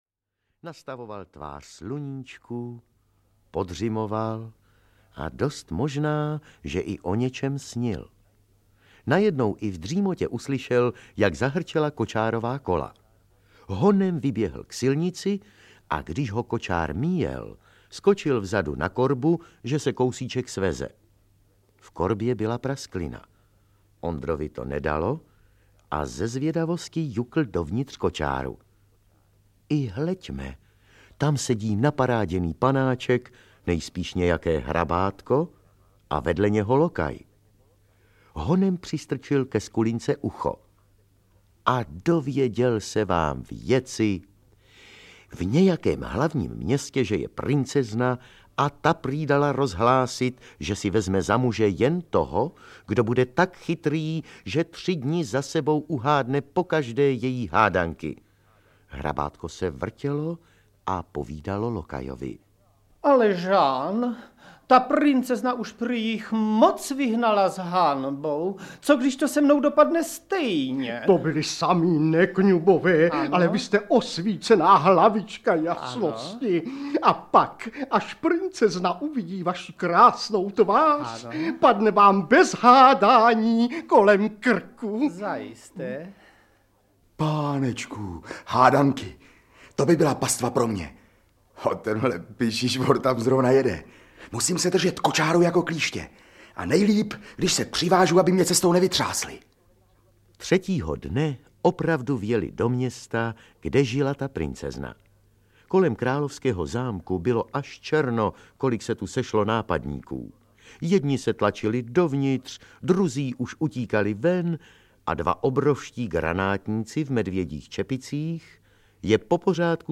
Ukázka z knihy
Pátý díl minisérie Album pohádek "Supraphon dětem" představuje další - tentokrát pouze dramatizované - pohádky ze supraphonského archivu.